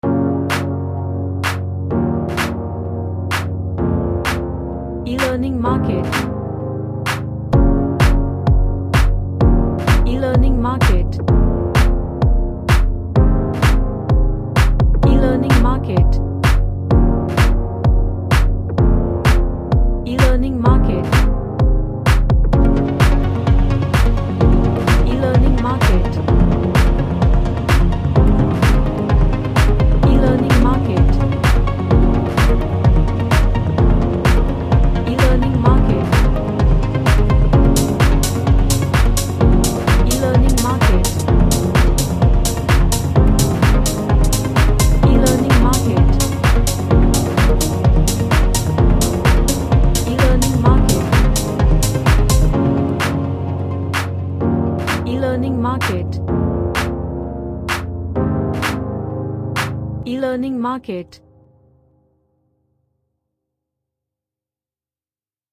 A track with Choirs.
Emotional